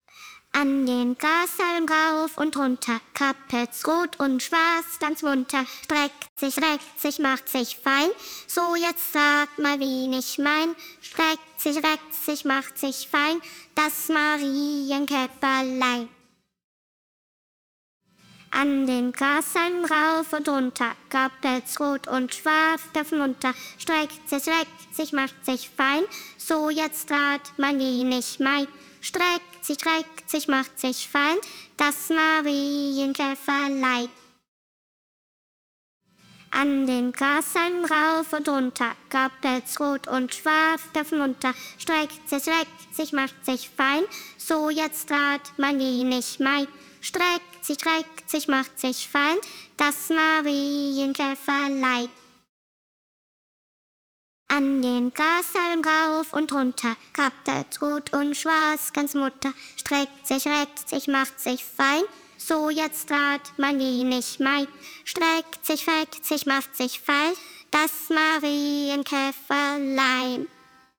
Auf der Wiese - Das Marienkäferlein - Einspielung des Sprechstücks
Auf-der-Wiese-_Sprechstimme-solo_.mp3